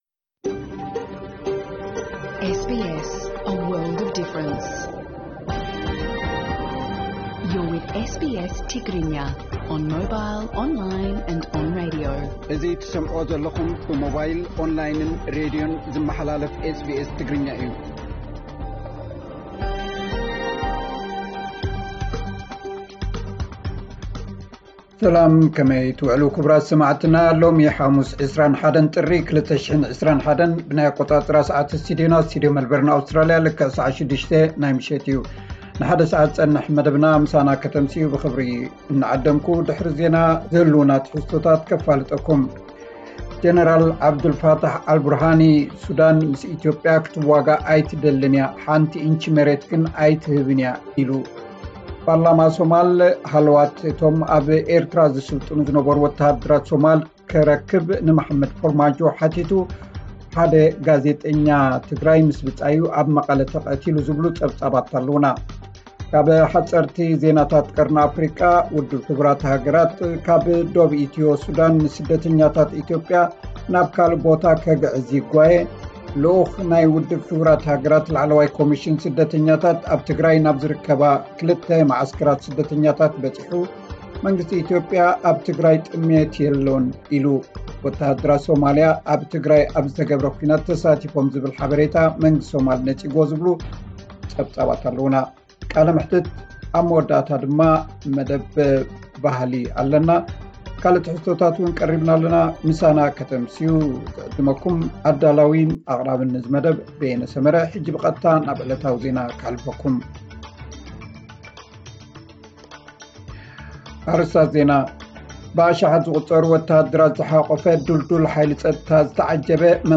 ዕለታዊ ዜና SBS ትግርኛ 21 ጥሪ 2021